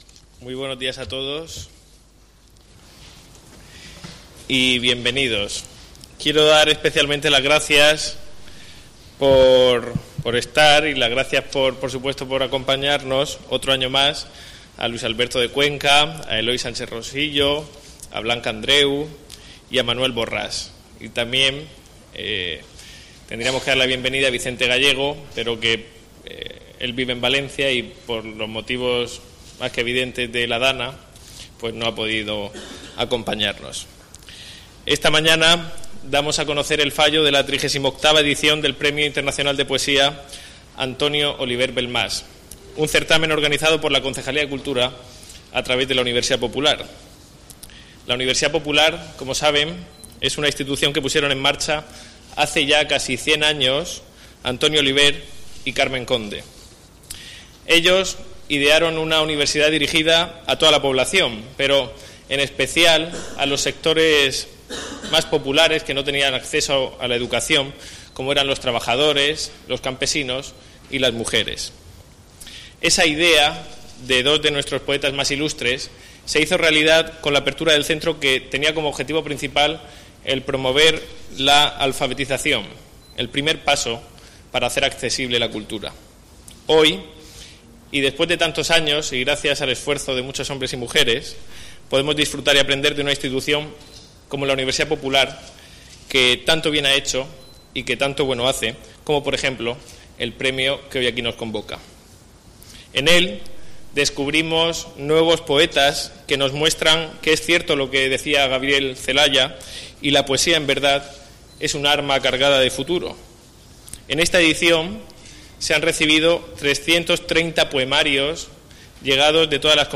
Audio: Presentaci�n del fallo del 38 Premio de Poes�a Antonio Oliver Belm�s (MP3 - 14,02 MB)